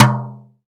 Brush Tom M2.wav